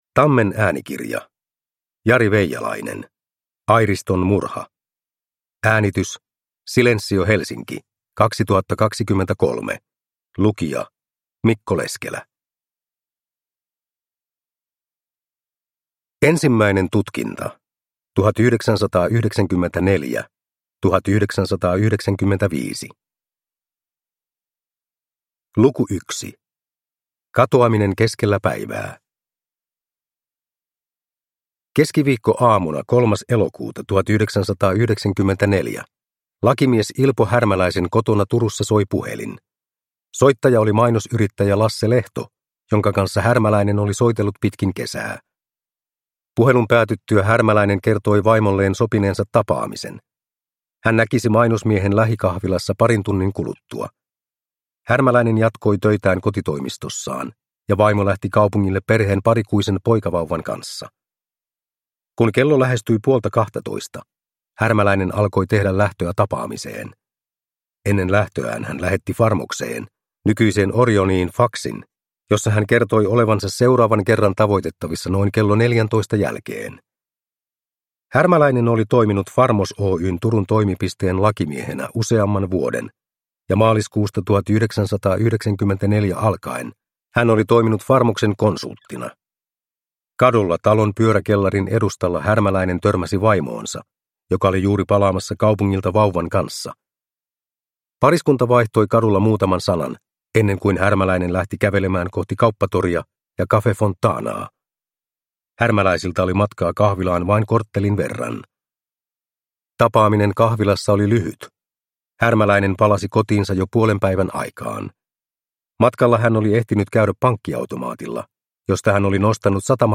Airiston murha – Ljudbok – Laddas ner